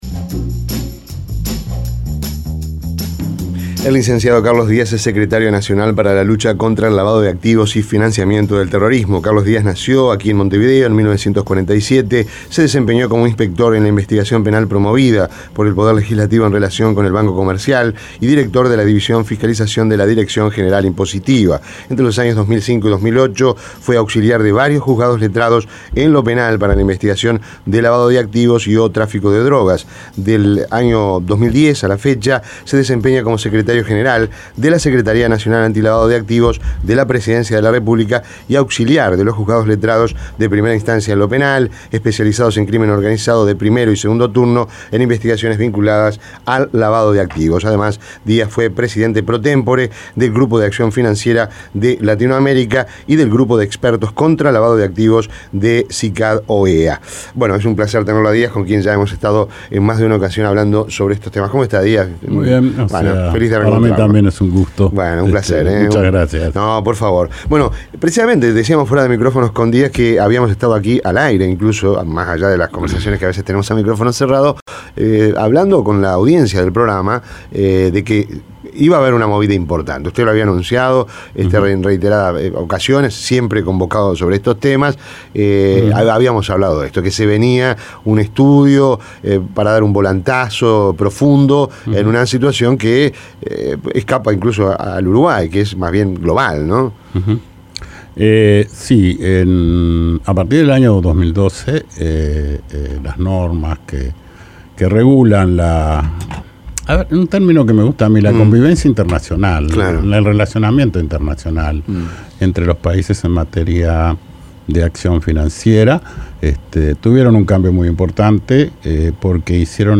ENTREVISTA EN ROMPKBZAS